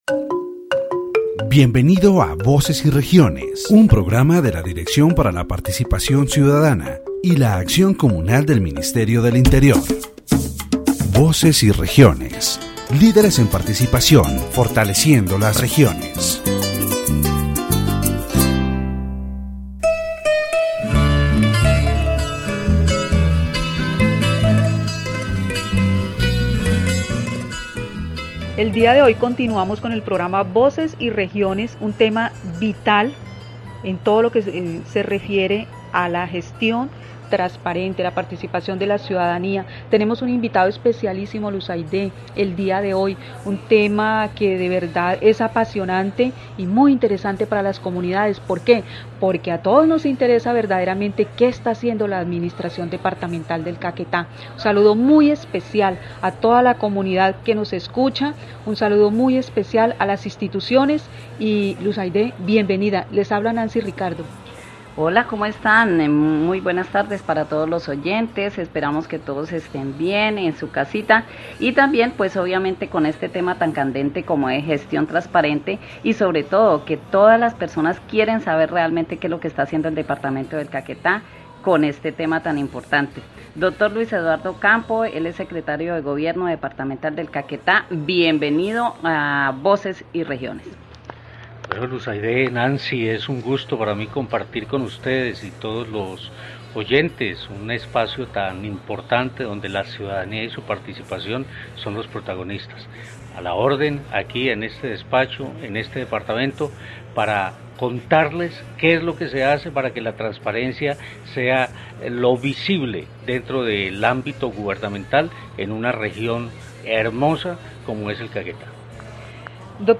The radio program "Voices and Regions" of the Directorate for Citizen Participation and Communal Action of the Ministry of the Interior focuses on transparent management in the department of Caquetá. In this episode, Dr. Luis Eduardo Campo, Secretary of the Departmental Government of Caquetá, discusses the measures and strategies implemented to ensure transparency in public administration. Topics such as applied ethics, the creation of specialized contracting offices, the delegation of responsibilities to office secretaries, and the importance of self-control and continuous evaluation are addressed.